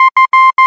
摩斯密码字母和数字" f
描述：摩斯密码字母数字
Tag: 字母 莫尔斯 数字